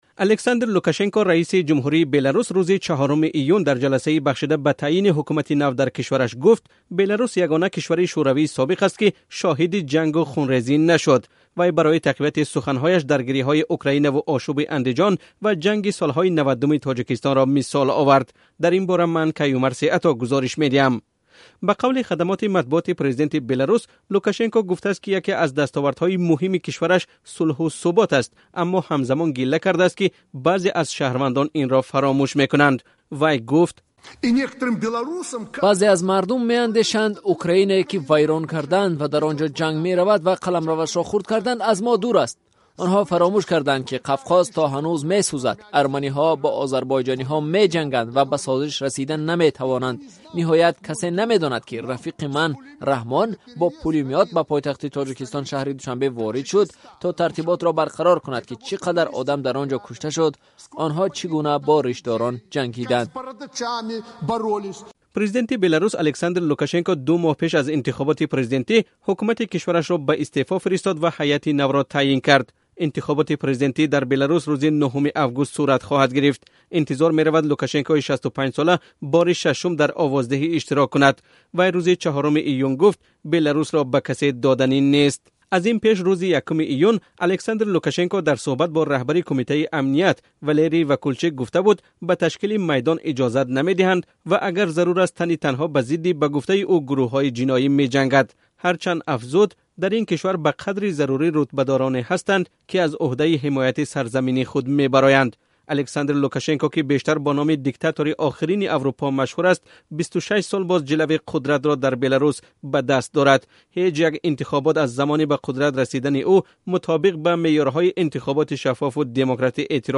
Александр Лукашенко, раиси ҷумҳурии Беларус, рӯзи 4-уми июн дар ҷаласаи бахшида ба таъйини ҳукумати нав дар кишвараш гуфт, Беларус ягона кишвари Шӯравии собиқ аст, ки шоҳиди хунрезӣ нашуд.